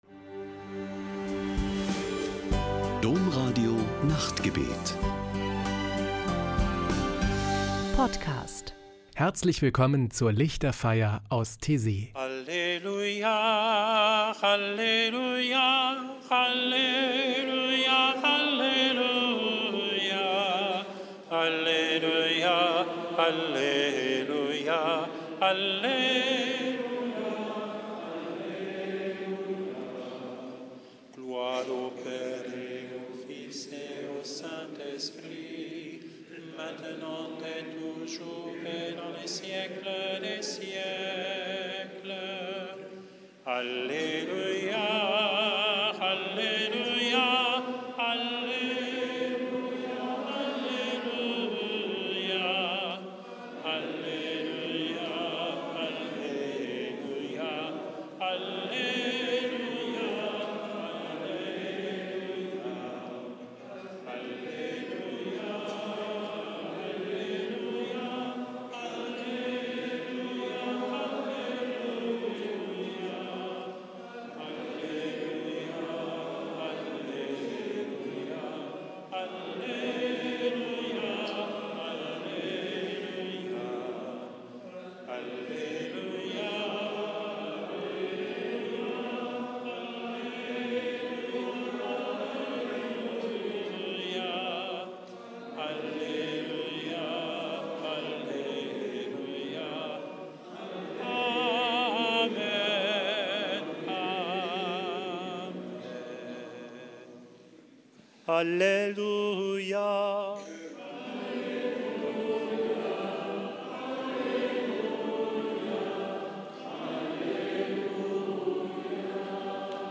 Die Lichterfeier aus Taizé: Spirituelle Gesänge und Gebete
Ein Höhepunkt jede Woche ist am Samstagabend die Lichterfeier mit meditativen Gesängen und Gebeten.